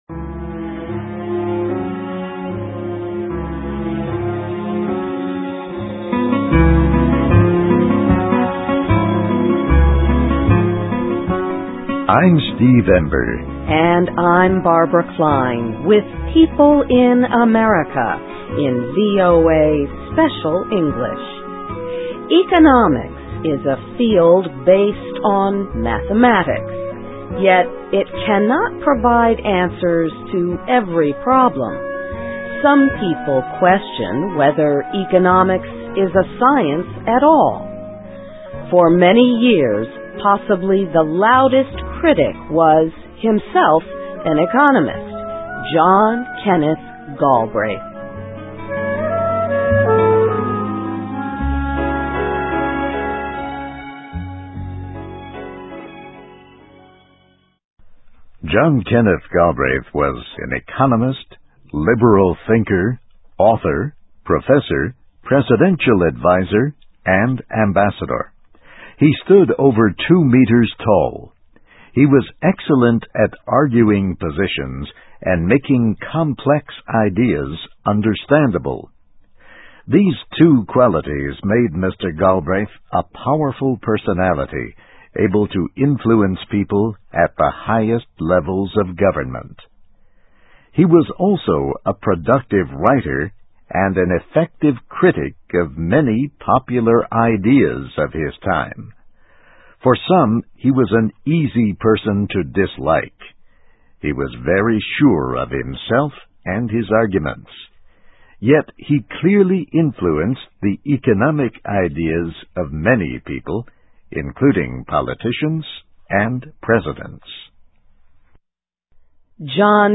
Biography - John Kenneth Galbraith, 1908-2006: He Strongly Influenced Economic Thought in the US (VOA Special English 2007-04-07)
Listen and Read Along - Text with Audio - For ESL Students - For Learning English